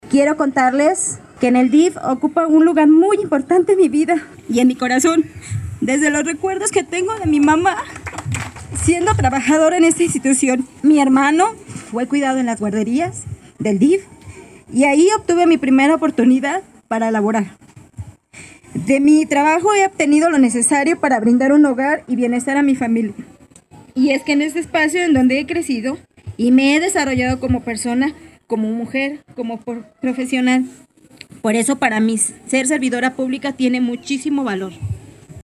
Valeria Alfaro, presidenta del Sistema DIF Municipal